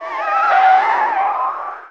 pgs/Assets/Audio/Vehicles_Engines_Motors/tyre_skid_02.wav at master
tyre_skid_02.wav